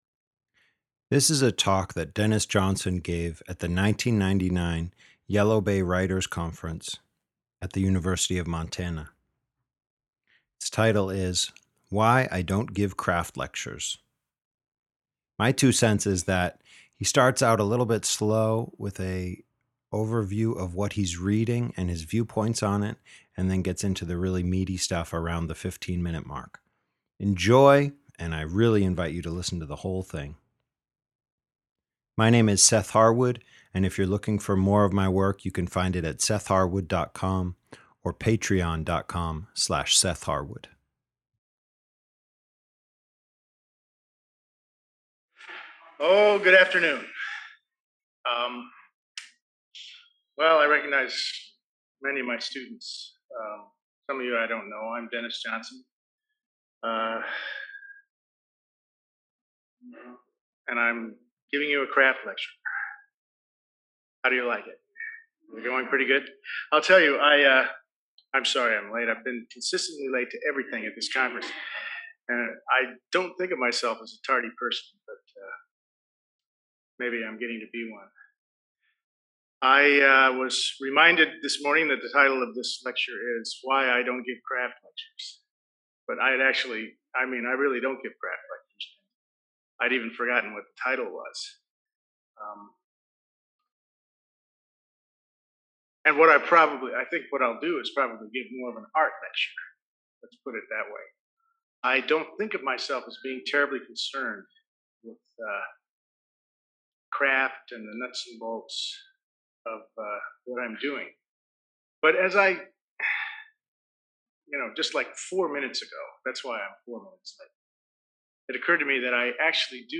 This happened at a workshop put on by the University of Montana at the Yellow Bay Writers Conference on Flathead Lake.